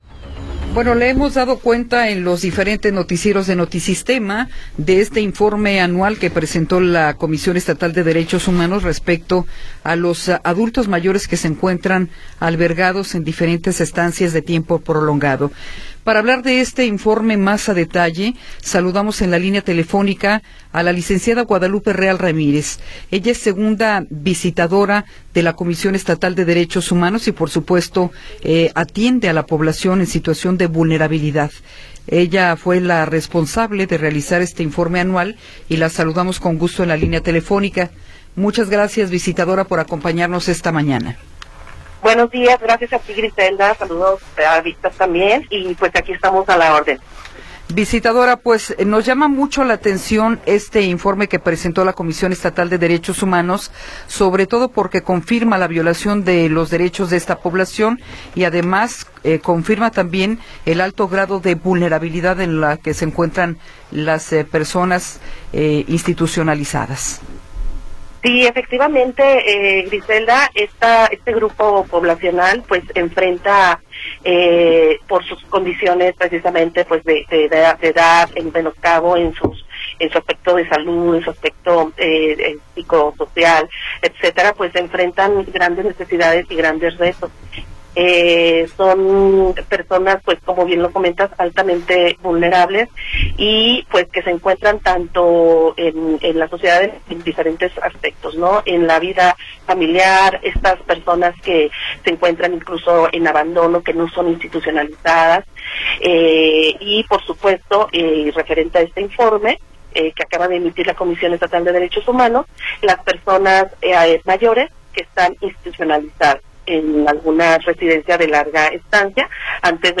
Entrevista con Guadalupe Real Ramírez
Guadalupe Real Ramírez, segunda visitadora general de la CNDH, nos habla sobre el informe especial en torno a la situación de adultos mayores en estancias de tiempo prolongado.